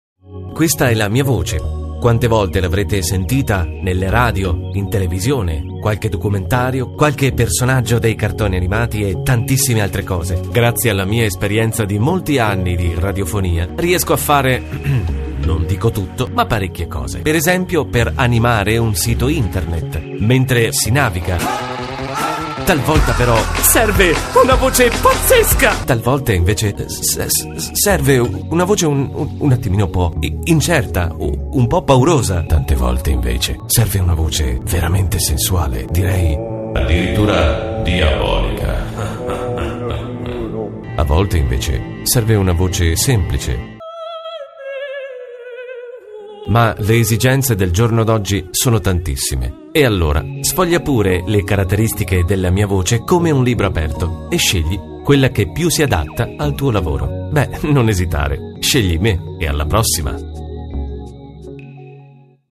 italienischer Sprecher.
Sprechprobe: Werbung (Muttersprache):
voice over artist italien.